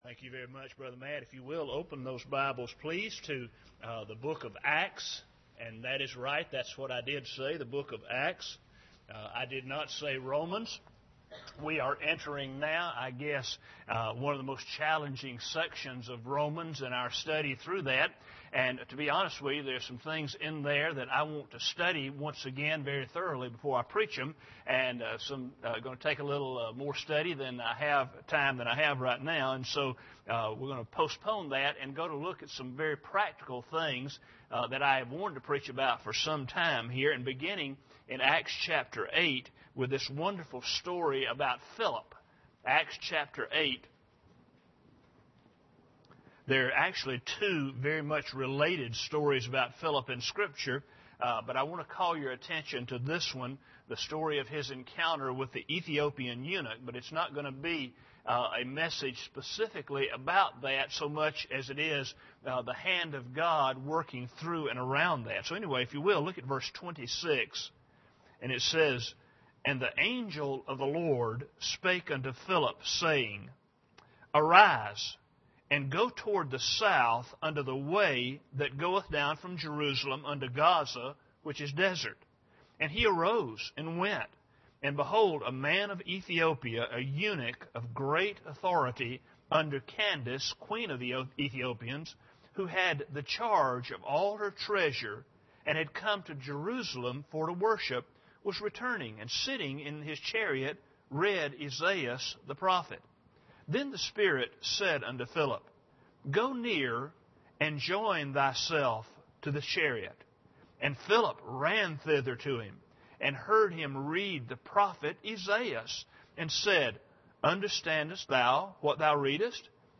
Acts 8:26-39 Service Type: Sunday Morning Bible Text